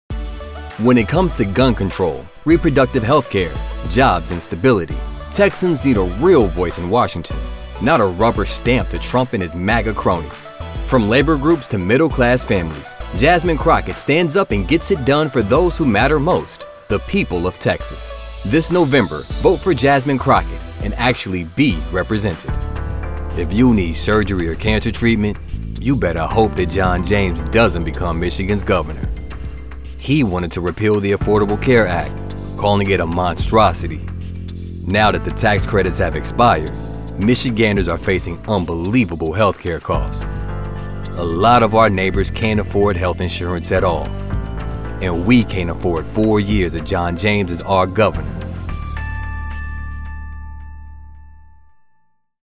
Male Democratic Voices
Voice actors with deep experience, pro home studios and Source Connect.